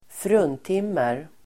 Ladda ner uttalet
Uttal: [²fr'un:tim:er]